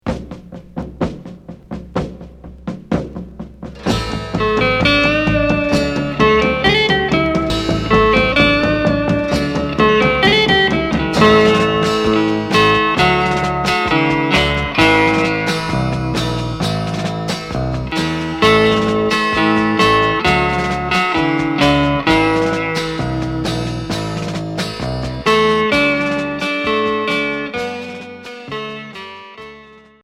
Rock 60's